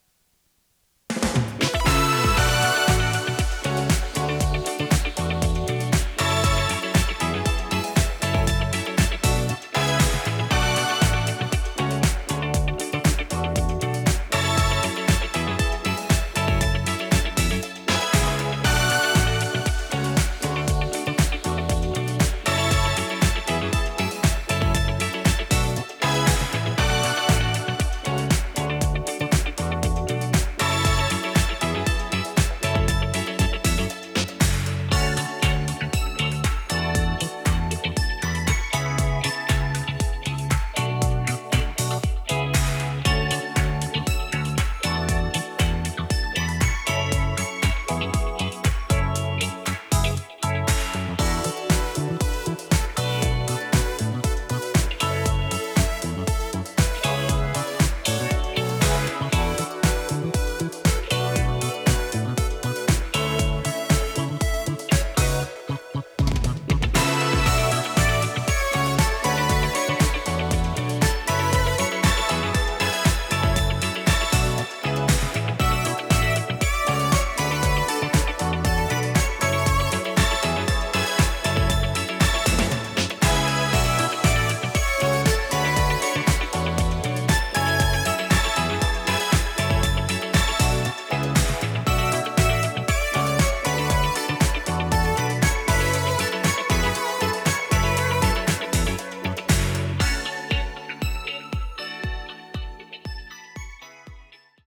テープ：RTM
ノイズリダクションOFF
【テクノポップ】96kHz-24bit 容量57.4MB